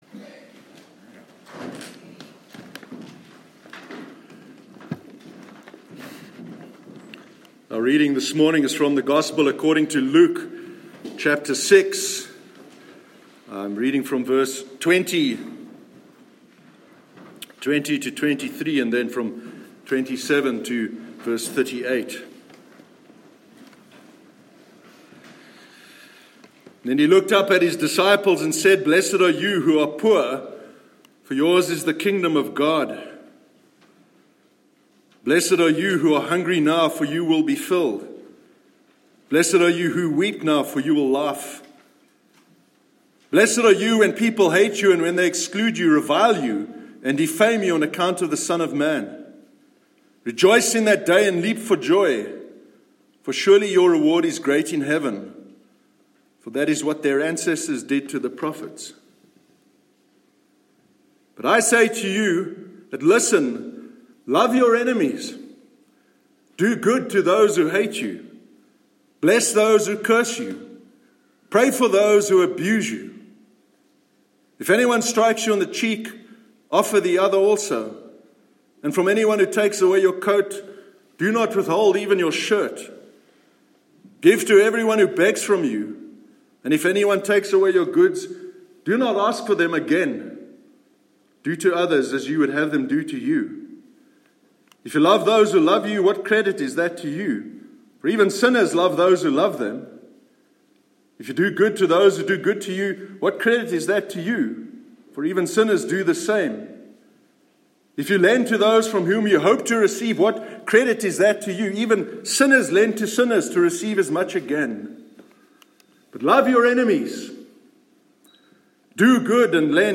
Loving Your Enemies- Sermon 9th June 2019
sermon-9-june-2019.mp3